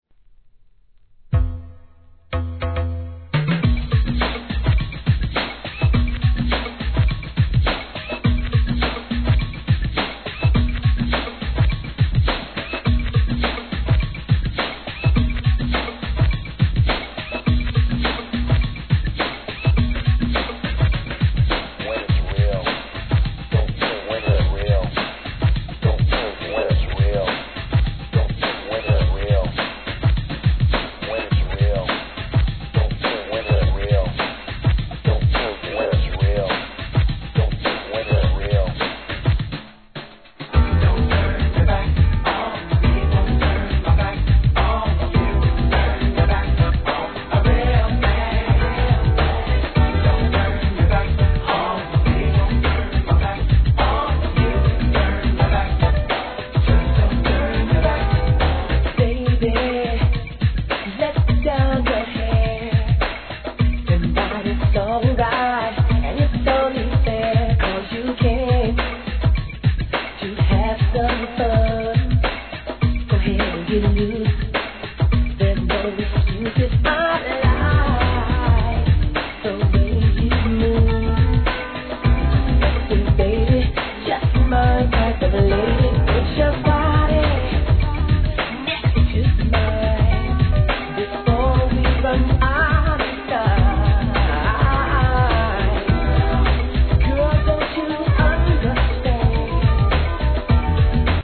HIP HOP/R&B
NEW JACK SWING期の見事な跳ねっぷりで裏CLASSIC認定!!!